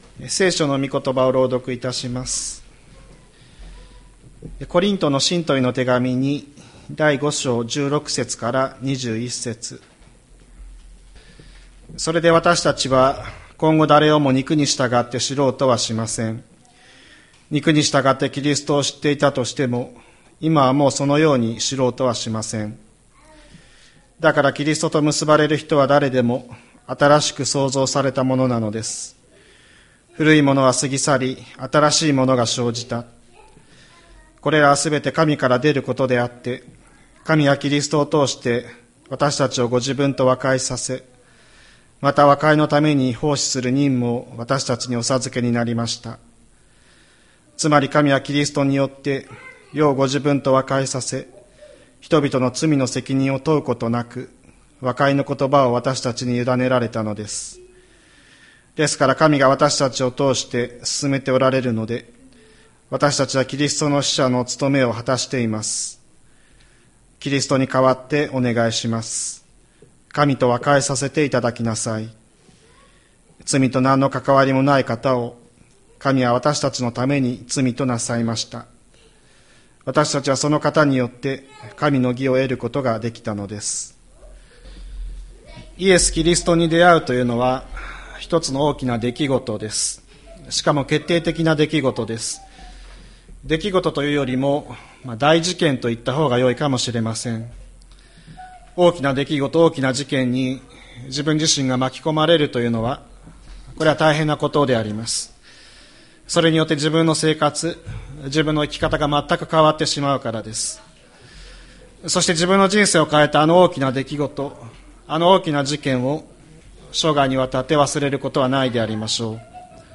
2025年02月02日朝の礼拝「新しい自分を生きる」吹田市千里山のキリスト教会
千里山教会 2025年02月02日の礼拝メッセージ。